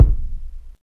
Kick (20).wav